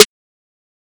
MZ Snare [Metro Generic Hi].wav